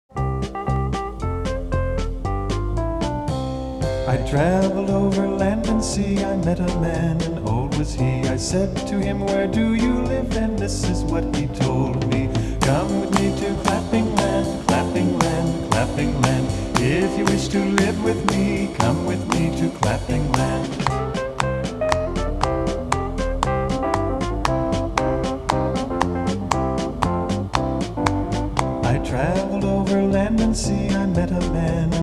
Home > Folk Songs